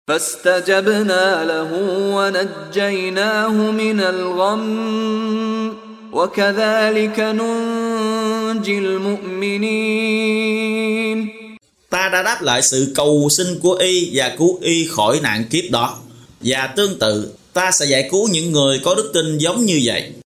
Đọc ý nghĩa nội dung chương Al-Ambiya bằng tiếng Việt có đính kèm giọng xướng đọc Qur’an